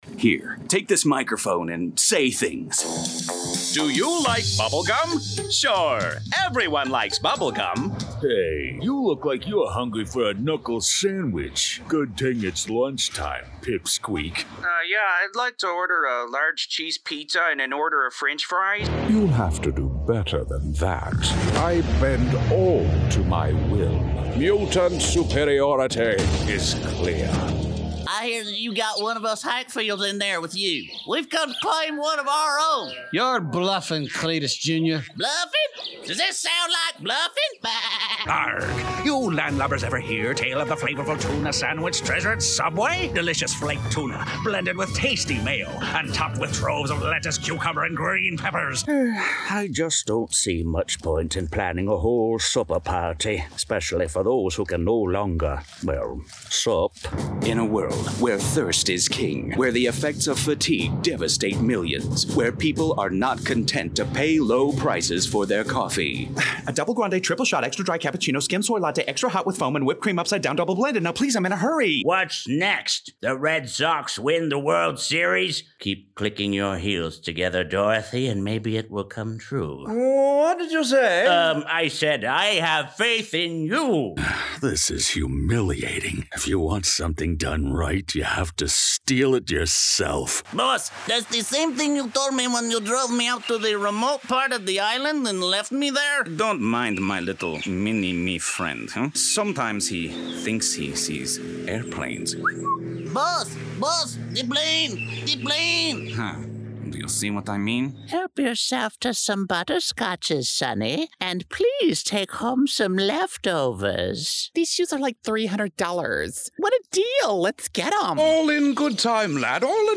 American, American Southern, British
Cartoon / Animation
I'm a professional voice actor recording from my Source-Connect Certified home studio in metro Atlanta:
⌲ Sennheiser MKH 416 Shotgun Mic
While clients like my friendly, calm, reassuring, relatable 'regular' voice (healthcare, investment, and mattress companies have shown me a lot of love), I have a strange range of tones, accents and dialects chambered and ready to roll.